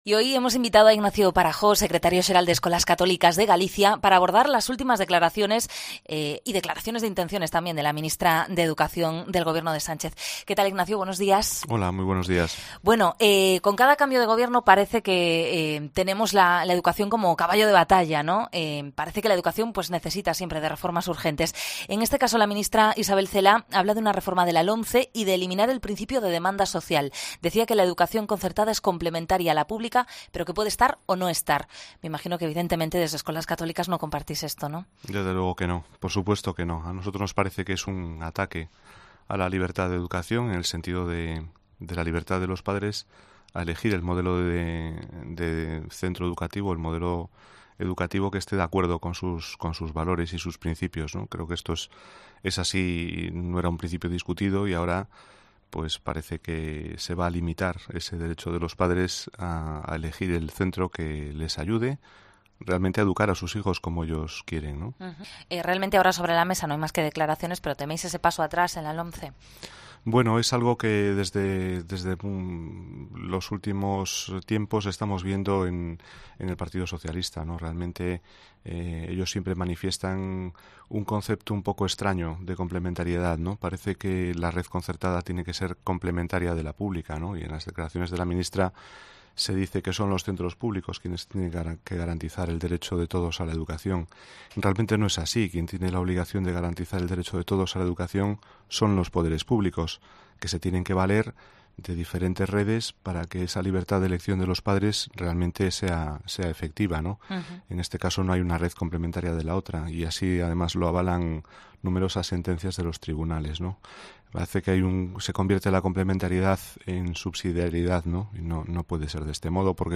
Entrevista a ESCOLAS CATÓLICAS